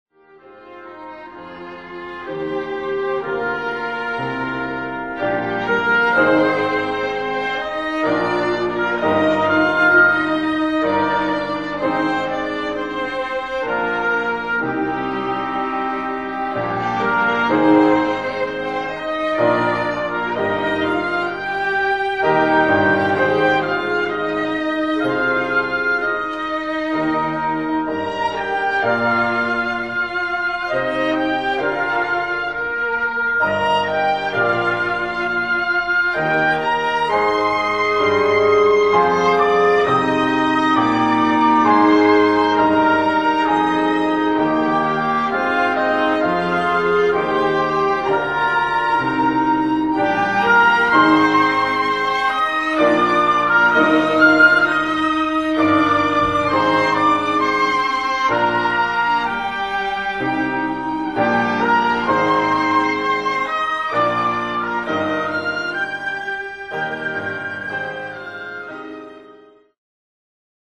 音楽ファイルは WMA 32 Kbps モノラルです。
Flute、Oboe、Clarinet、Violin、Cello、Piano